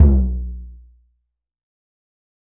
Index of /90_sSampleCDs/AKAI S6000 CD-ROM - Volume 5/Brazil/SURDO